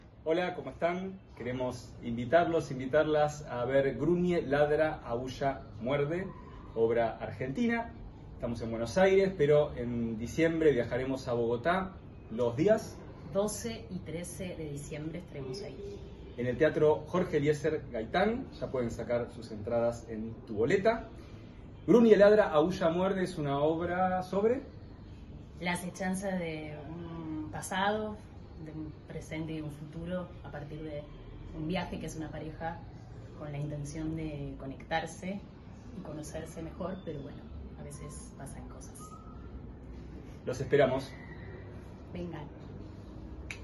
Voz en off